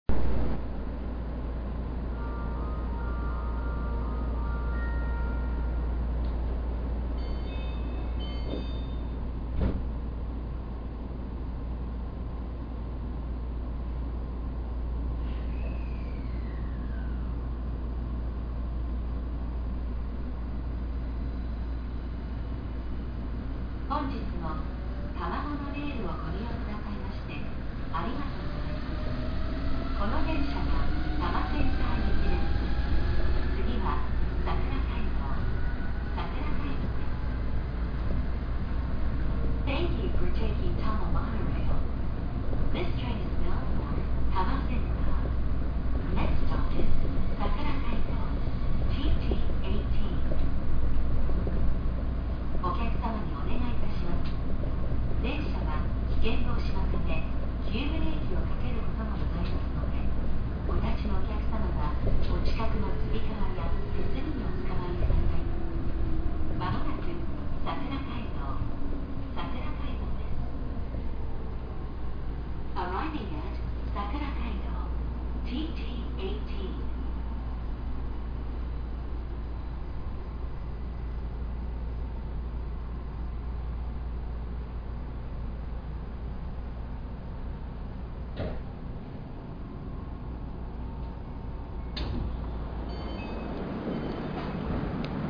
〜車両の音〜
・1000系走行音
【多摩都市モノレール】上北台→桜街道（1分29秒：746KB）
一応日立IGBTなのですがあまりモーター音は目立ちません。ドアチャイムは東京モノレールなどと同じものを使用しています。